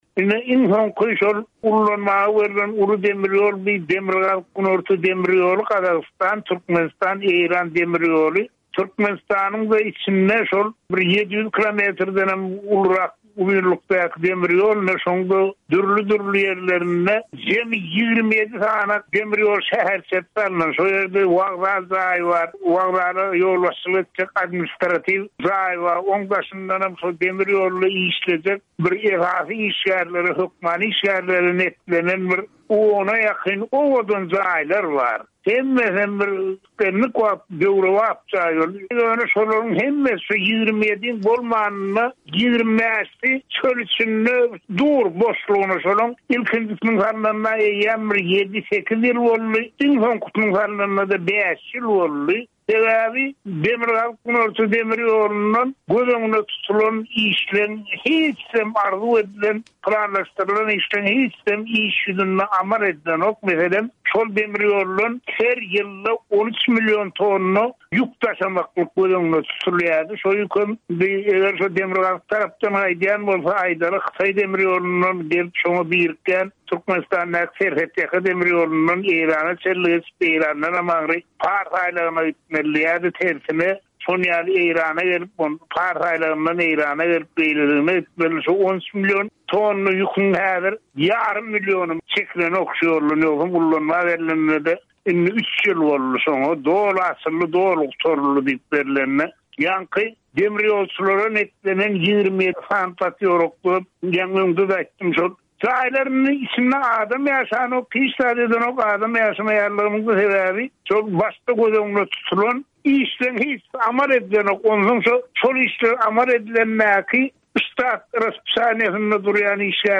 söhbet